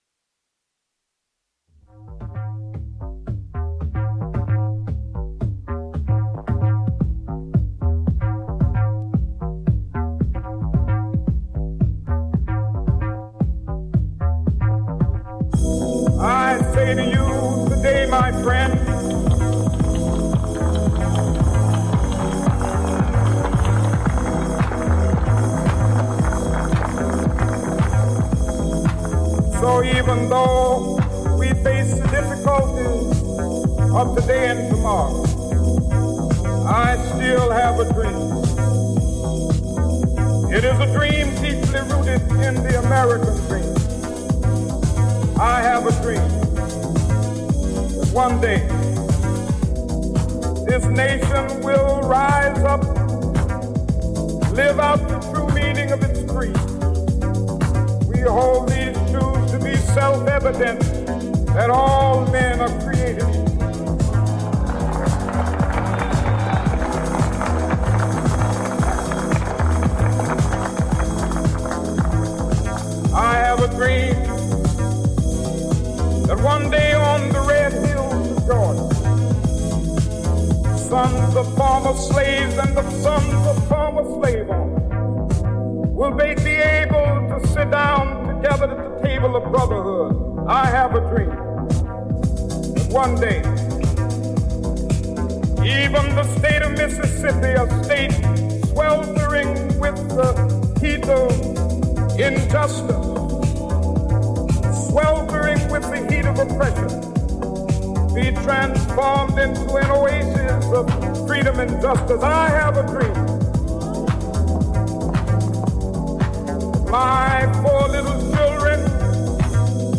ジャンル(スタイル) CLASSIC HOUSE / DEEP HOUSE / CHICAGO HOUSE